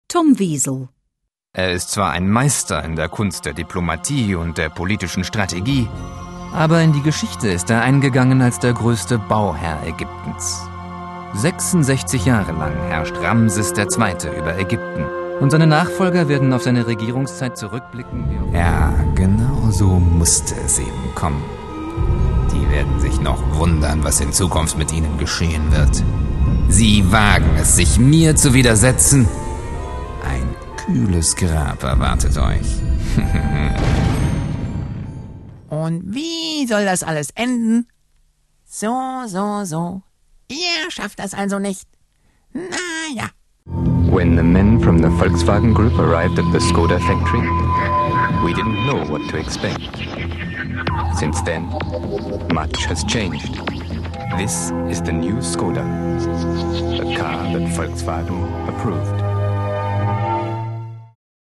German voiceover artist: contact his agent direct for male German voice overs